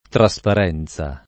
[ tra S par $ n Z a ]